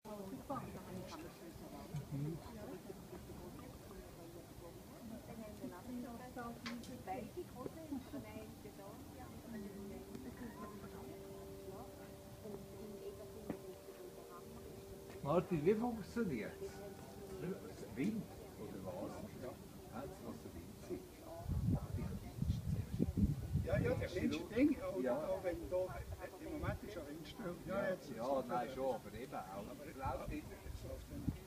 Wind Sculptures
Near the restaurant Bluemlismatt in Egerkingen (Switzerland), there is a nice wind sculpture made by Toni Obrist.
wind.ogg